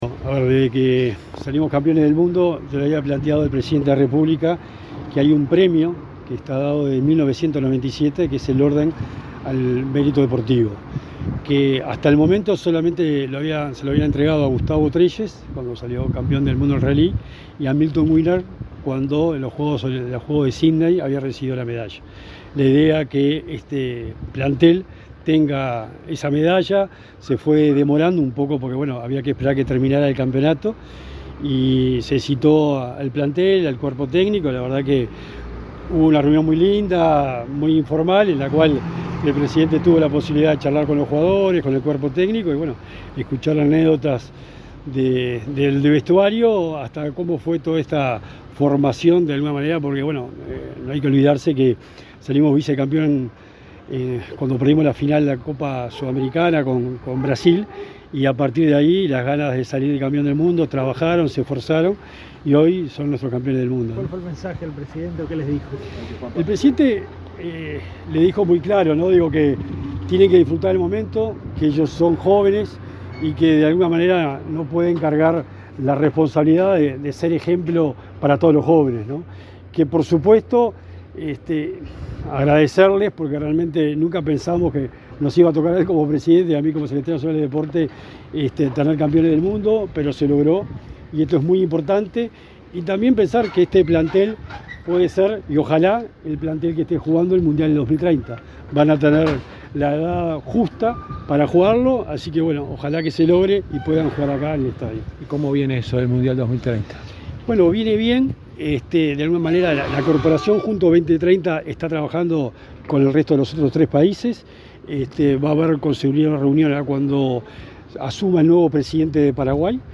Declaraciones del secretario nacional del Deporte, Sebastián Bauzá
El secretario nacional del Deporte, Sebastián Bauzá, dialogó con la prensa, luego del reconocimiento del presidente Luis Lacalle Pou a la delegación